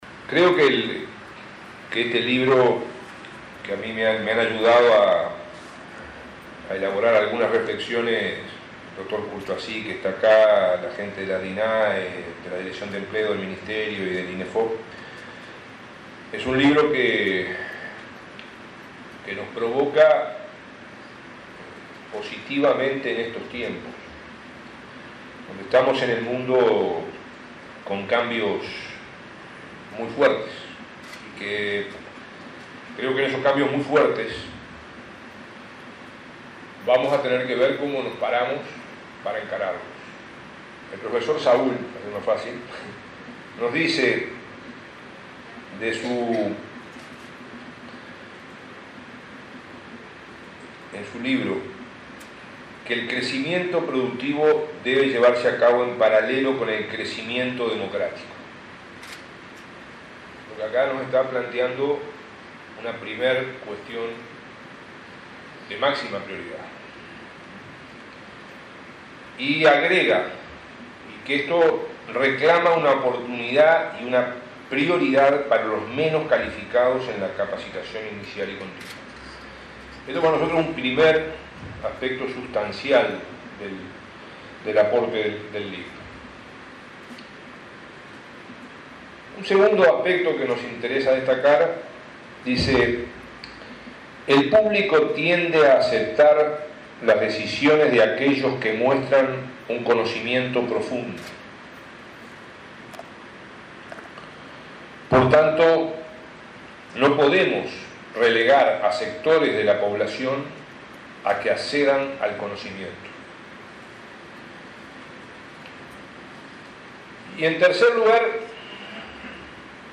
“Estamos en el mundo con cambios muy fuertes y tenemos que ver cómo nos paramos para encararlos”, afirmó el ministro de Trabajo, Ernesto Murro, en la presentación del libro de Saul Meghnagi “El saber profesional. Competencias, derechos, democracia”. Apeló a la reivindicación de valores, la profesionalización de los trabajadores y la universalización del conocimiento para la generación de mayor justicia social.